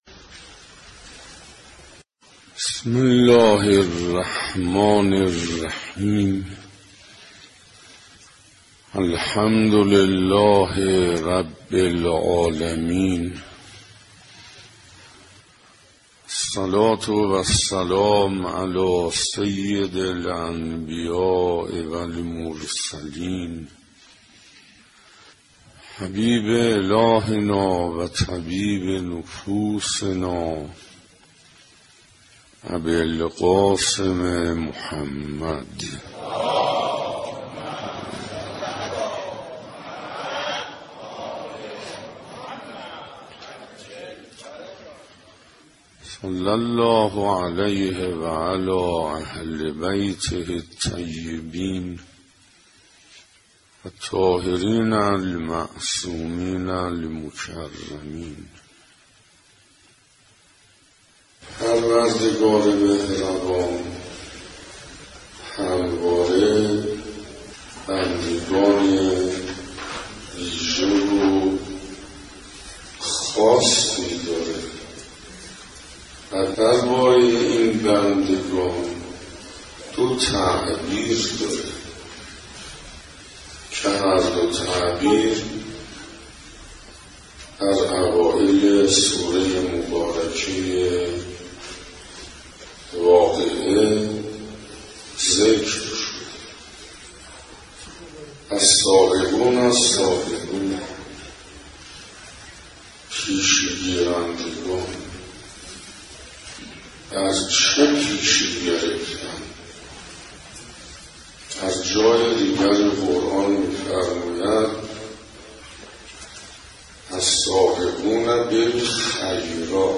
دانلود هفتمین جلسه از بیانات آیت الله حسین انصاریان با عنوان «ارزشهای ماه رمضان»
در این بخش به مناسبت فرا رسیدن ماه مبارک رمضان، هفتمین جلسه از بیانات آیت الله حسین انصاریان با عنوان «ارزشهای ماه رمضان» را تقدیم میهمانان ضیافت الهی می نماییم.